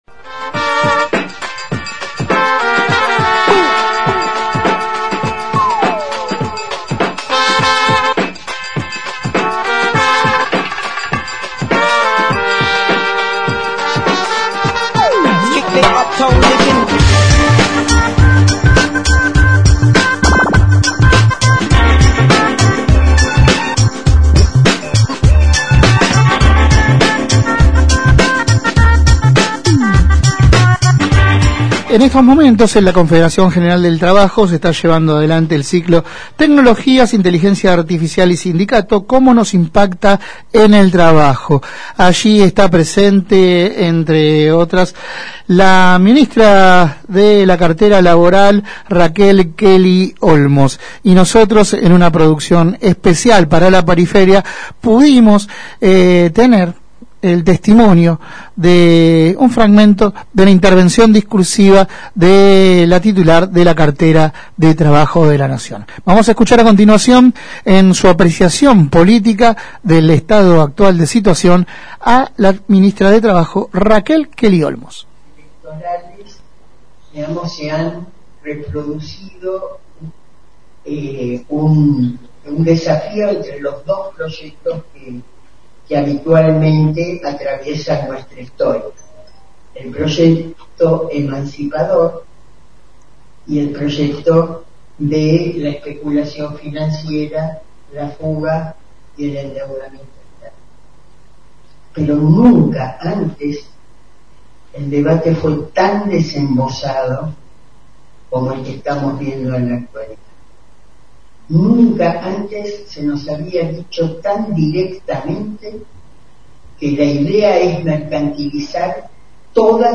Compartimos fragmentos de su intervención discursiva: